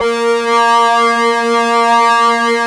45 SYNTH 1-R.wav